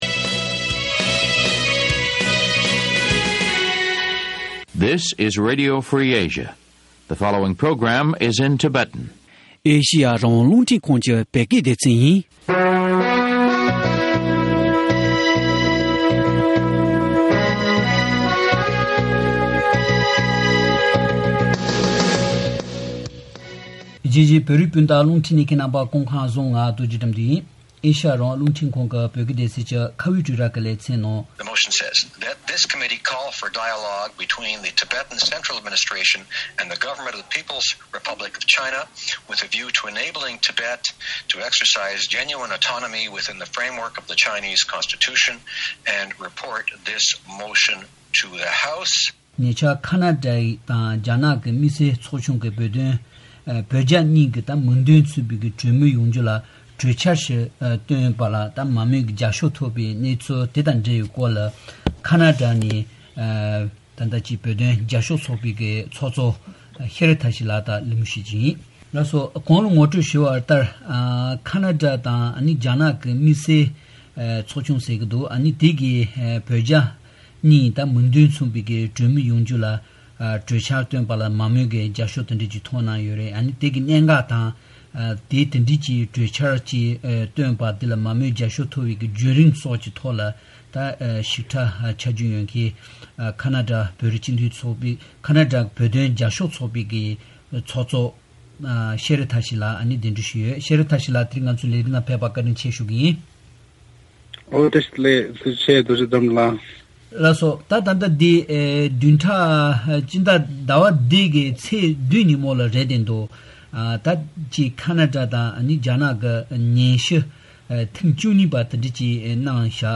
བཅའ་འདྲི་བྱེད་པ་ཞིག་ཉན་རོགས་ཞུ།།